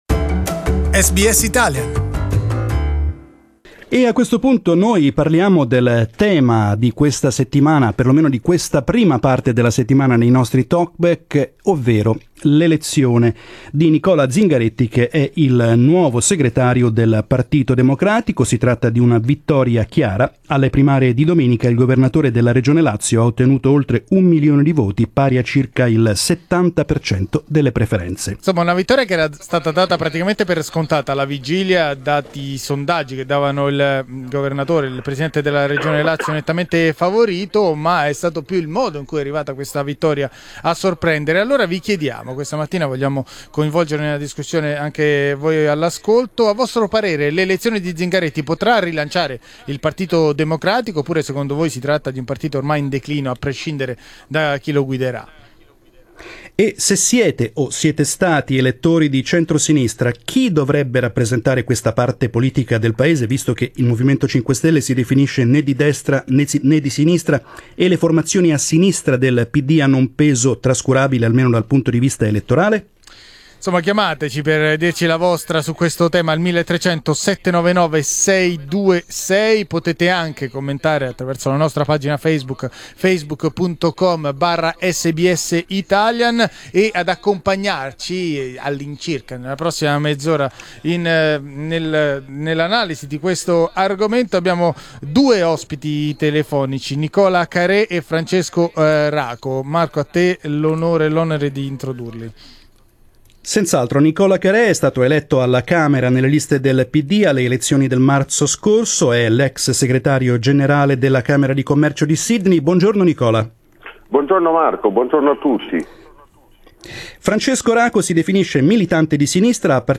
We asked our listeners what they think of the new PD leader.